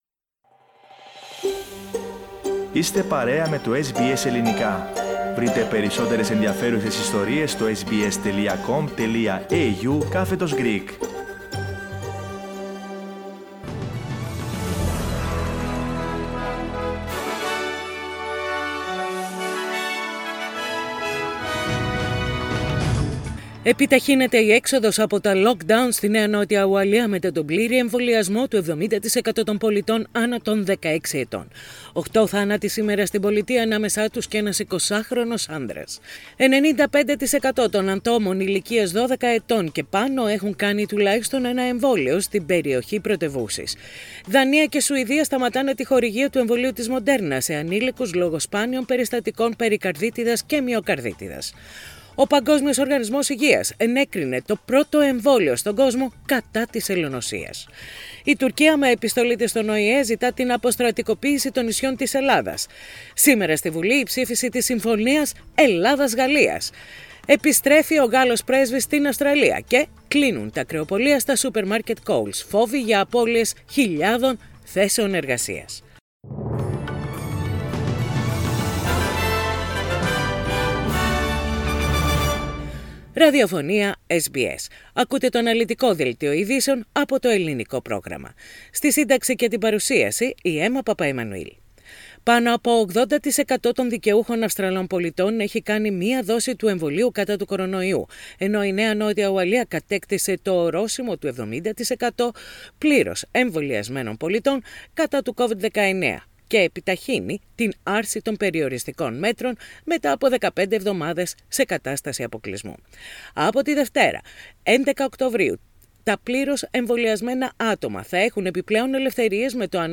News in Greek - Thursday 7.10.21